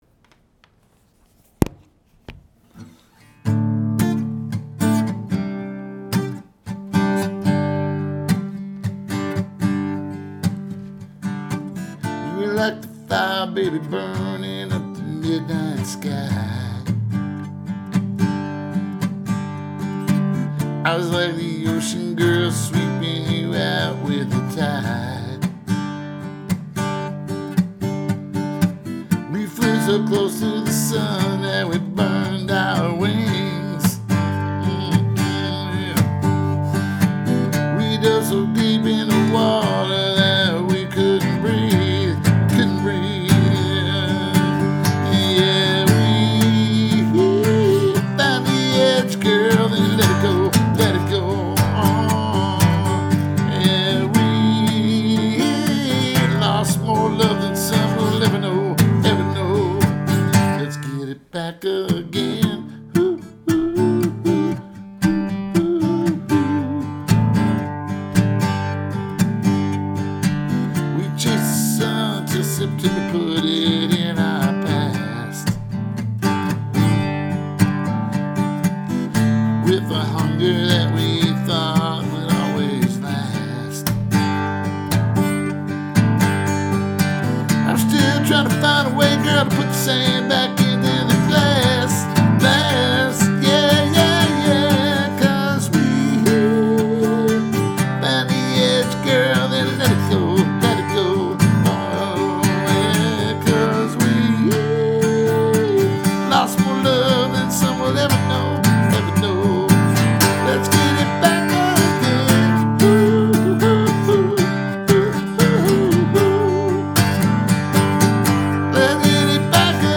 Genre: singer/songwriter, folk
Tags: male vocalist, uptempo, hopeful